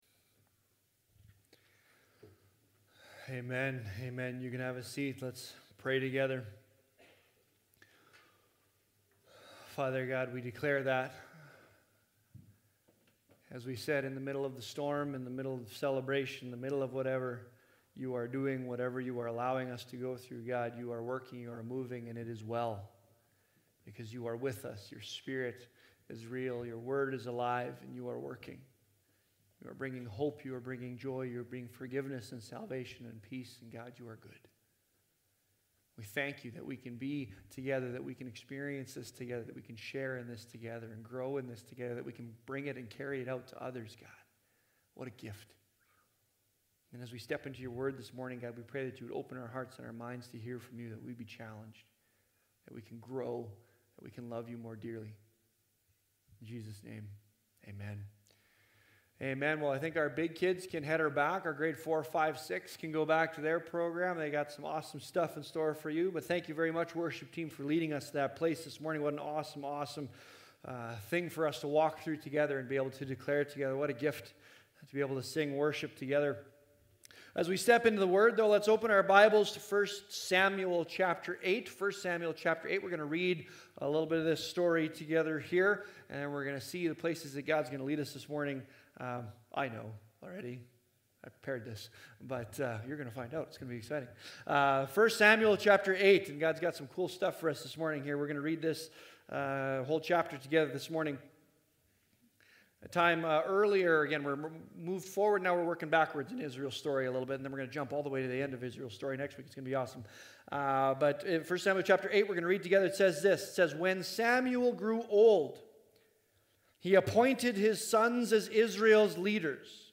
Sermons | Leduc Fellowship Church